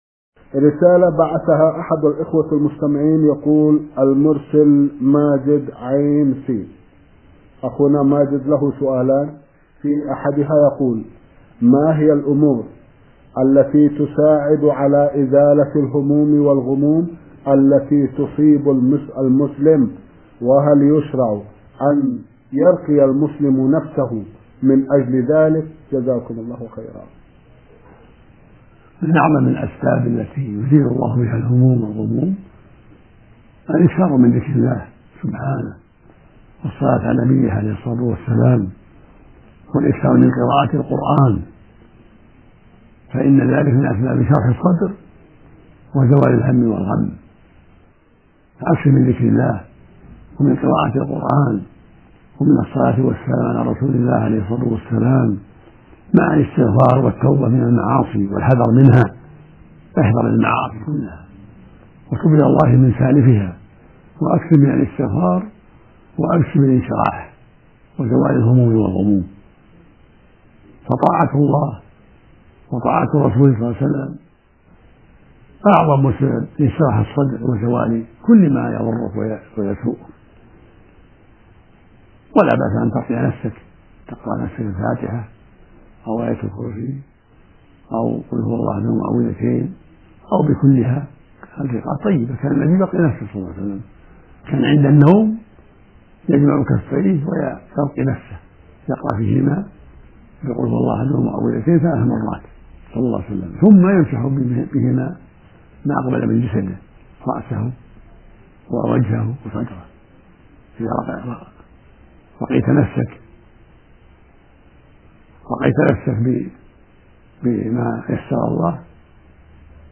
من مواعظ أهل العلم
Mono